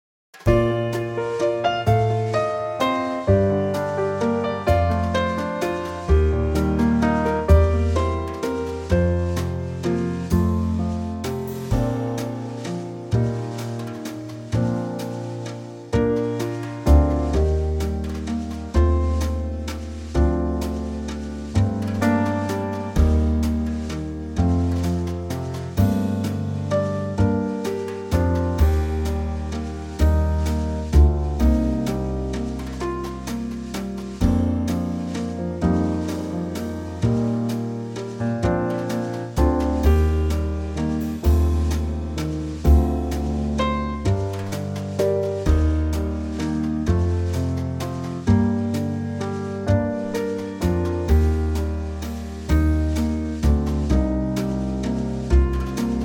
Unique Backing Tracks
key - Bb to B - vocal range - F to G#